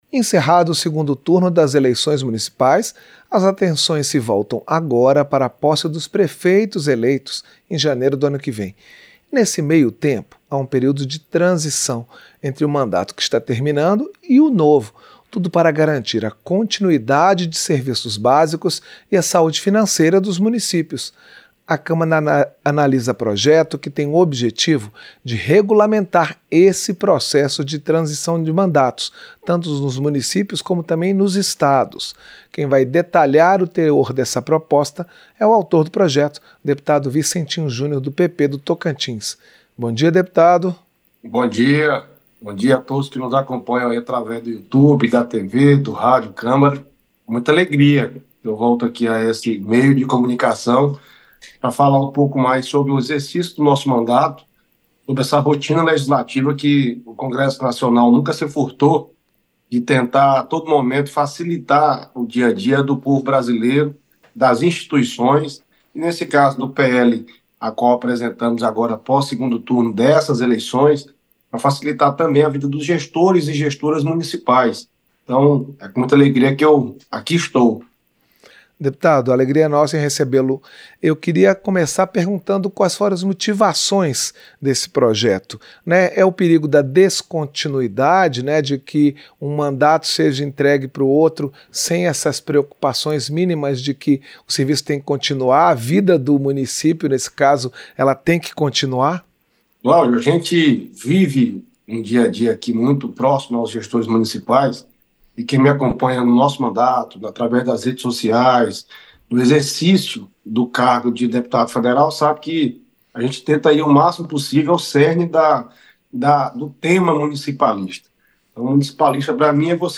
Entrevista - Dep. Vicentinho Júnior (PP-TO)